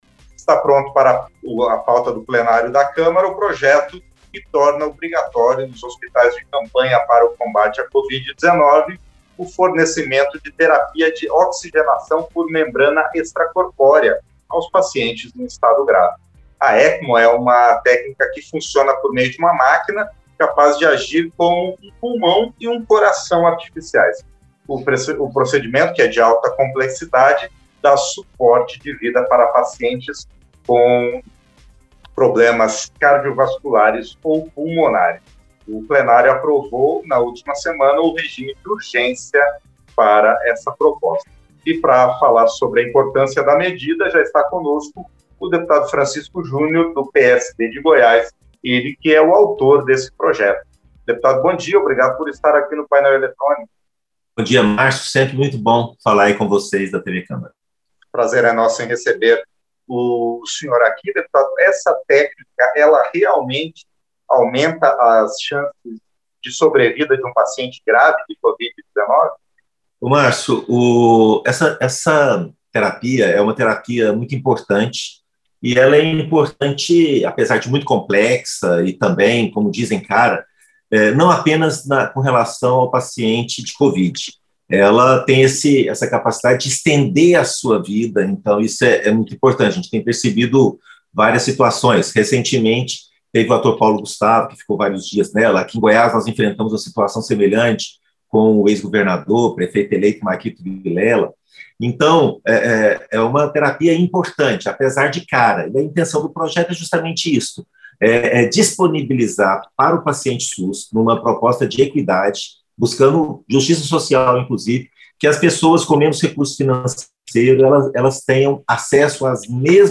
Entrevista - Dep. Francisco Jr. (PSD-GO)